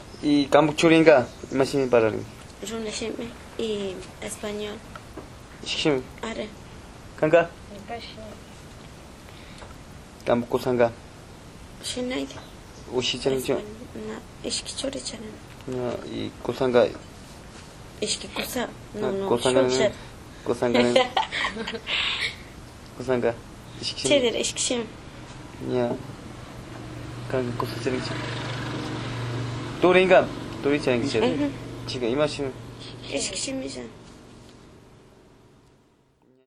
Entrevistas - Santa Cruz